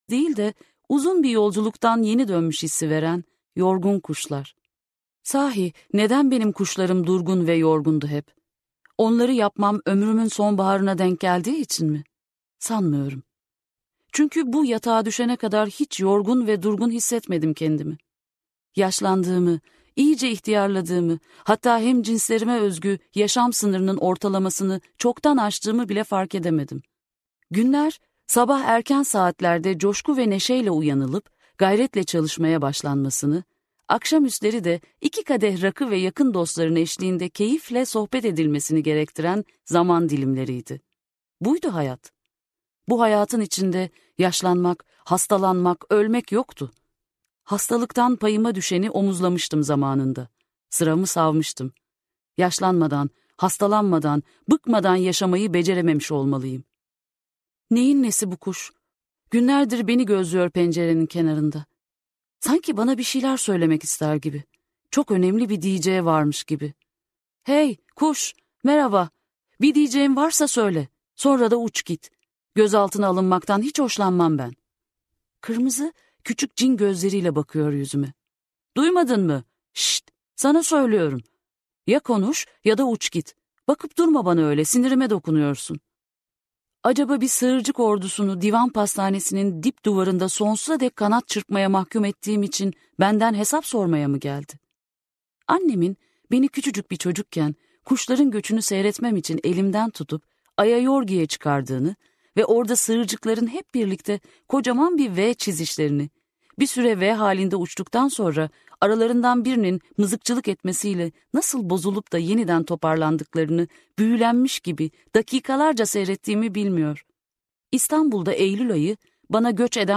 Füreya - Seslenen Kitap